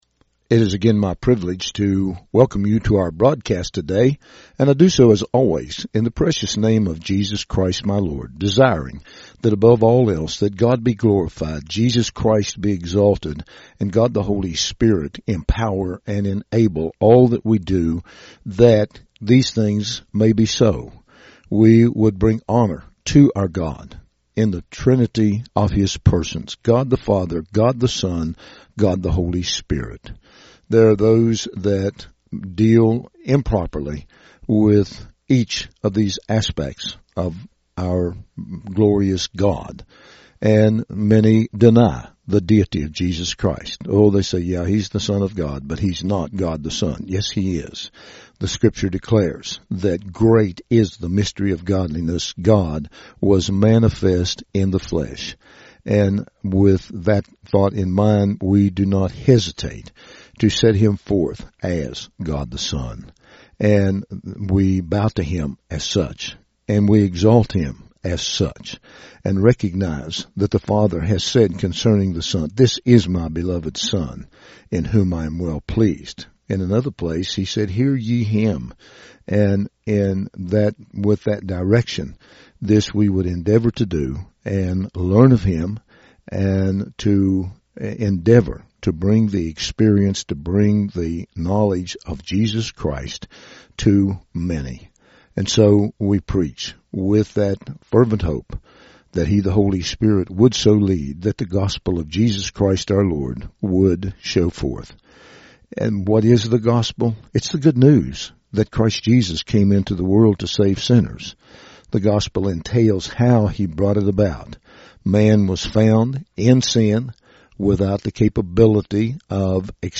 RADIO MESSAGE